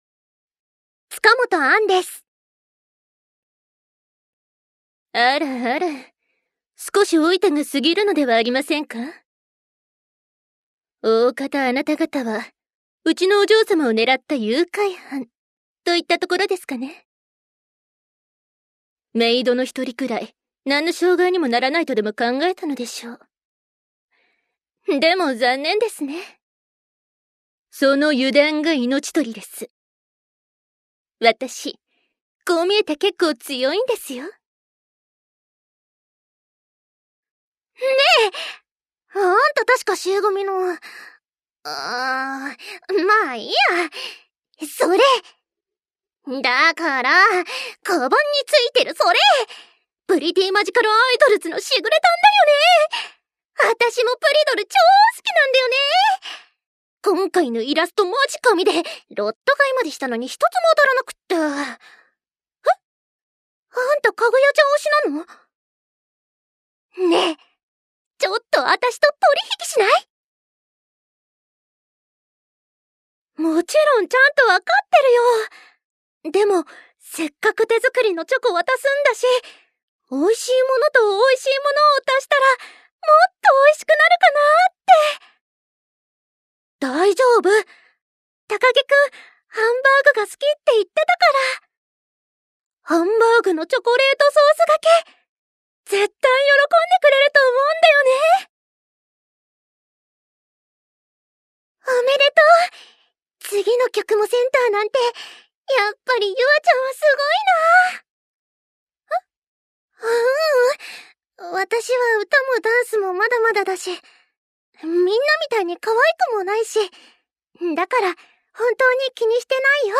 jr. voice actor
サンプルボイス